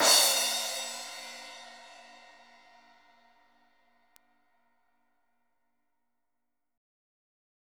CRASH 2   -L.wav